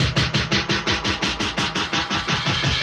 Index of /musicradar/rhythmic-inspiration-samples/85bpm
RI_DelayStack_85-06.wav